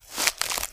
STEPS Bush, Walk 25.wav